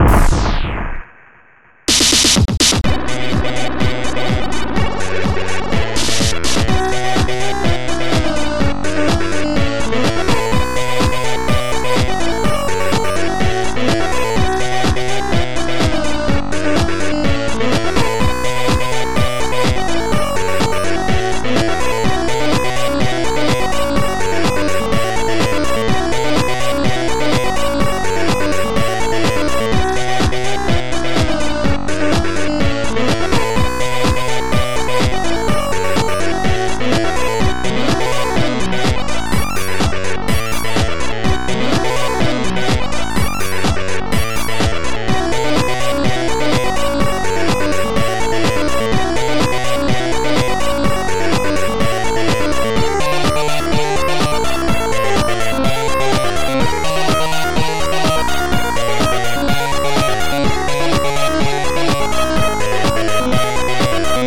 Protracker Module
Type Protracker and family